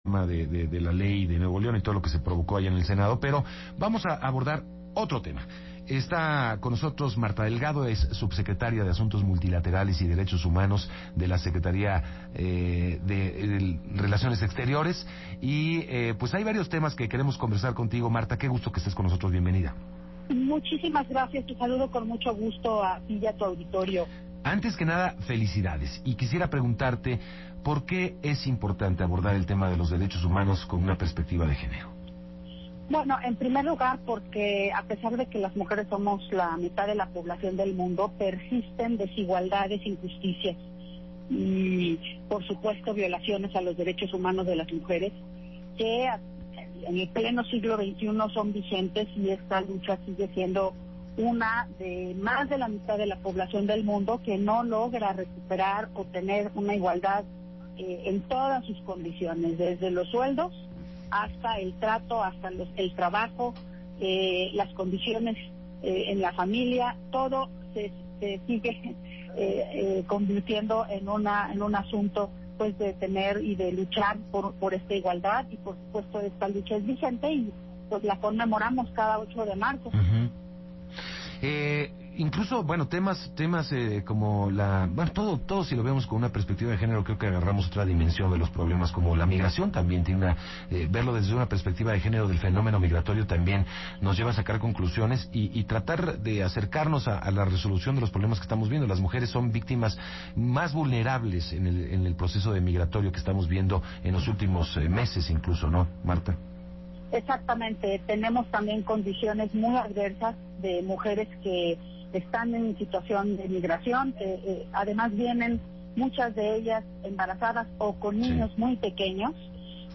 El 8 de marzo de 2019, Martha Delgado, subsecretaria para Asuntos Multilaterales y Derechos Humanos de la Secretaría de Relaciones Exteriores platicó en entrevista sobre la colaboración con la CIDH en el caso de la desaparición de los 43 estudiantes de Ayotzinapa.